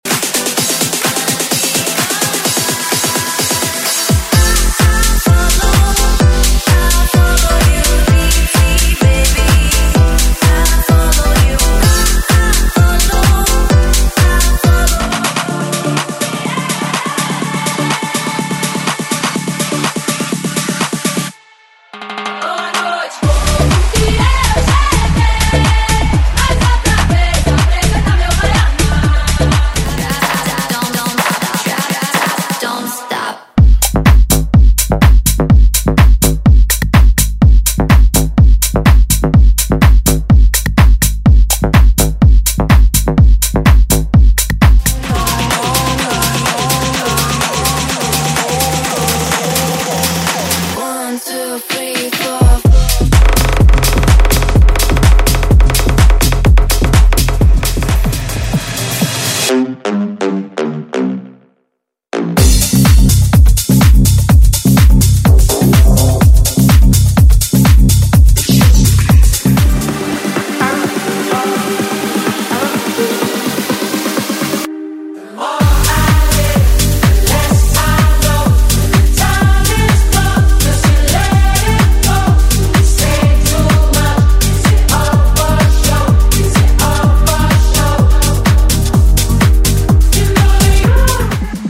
• Tech House = 100 Músicas
• Versões Extended
• Sem Vinhetas